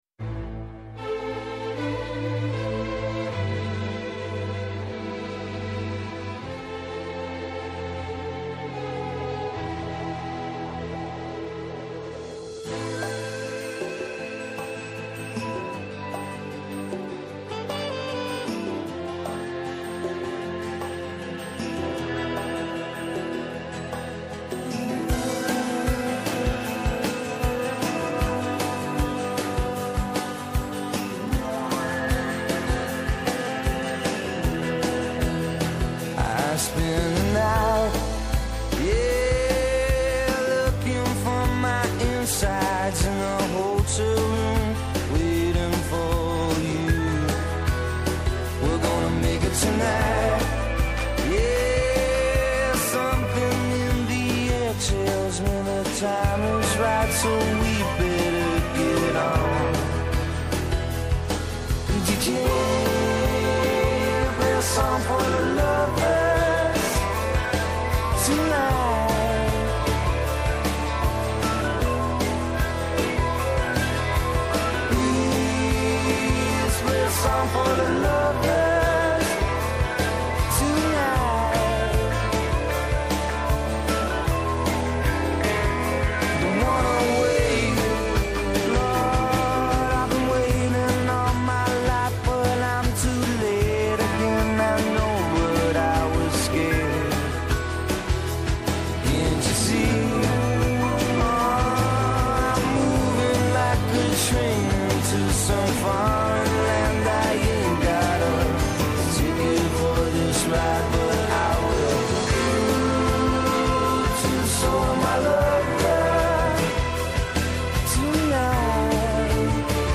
-H ενημέρωση του κυβερνητικού εκπροσώπου Παύλου Μαρινάκη